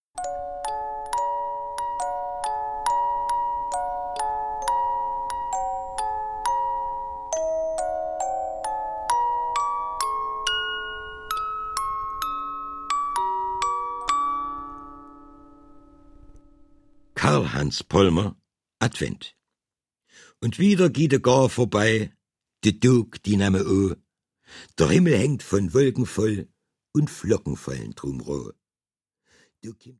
Deutsch - Mundart